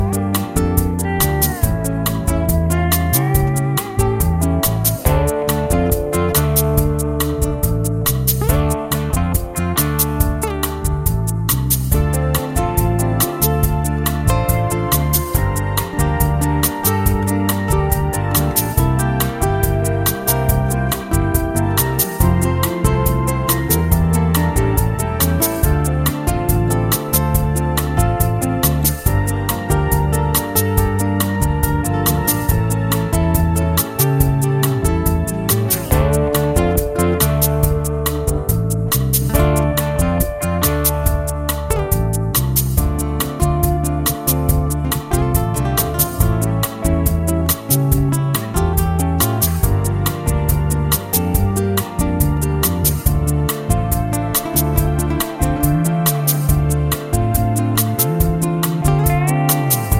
Soft Rock